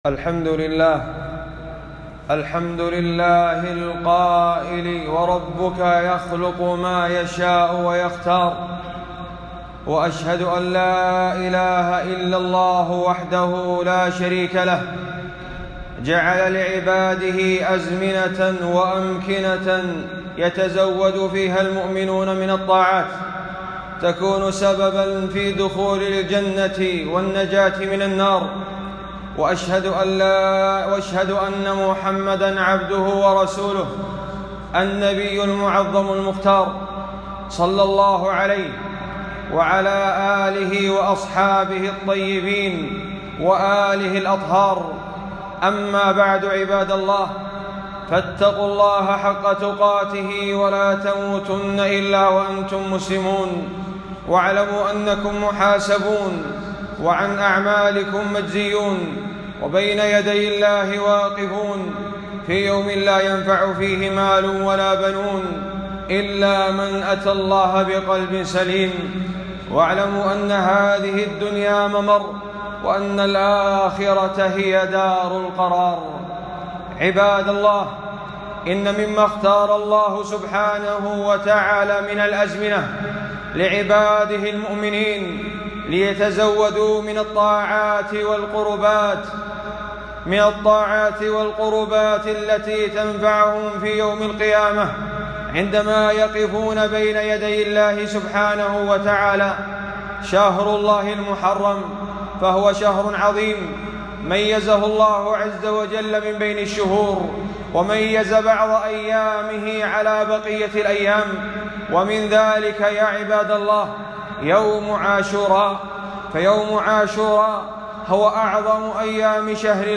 خطبة - بيان فضل عاشوراء والرد على المشككين 4-1-1443